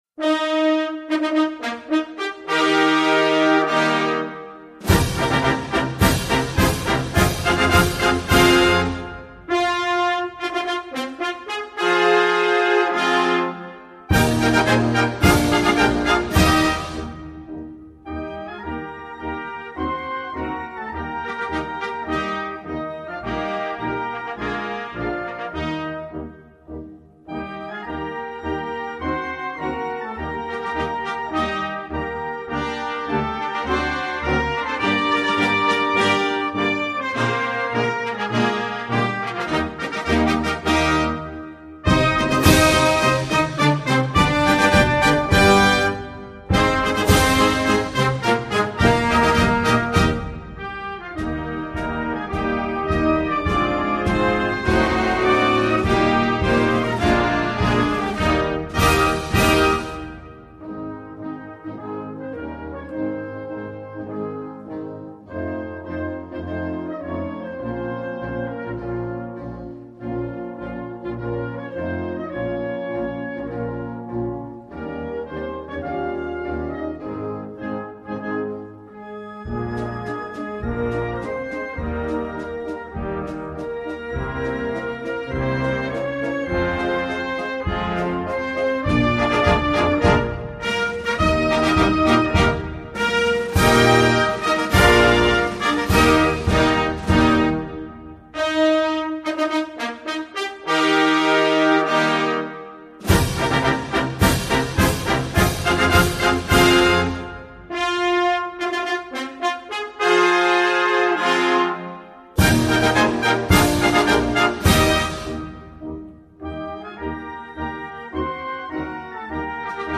United_States_Navy_Band_-_¡Oh,_gloria_inmarcesible!.mp3